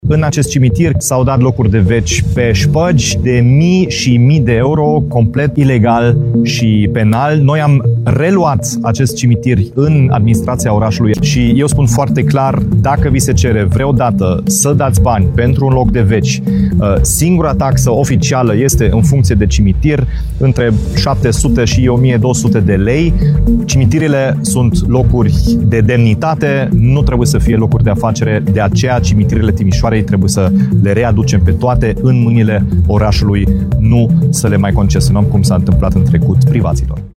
Primarul Dominic Fritz spune că cimitirul de pe Calea Buziașului va fi administrat de Horticultura, la fel ca cele de pe Calea Șagului și de pe strada Rusu Șirianu.
02-Dominic-Fritz-cimitire-20.mp3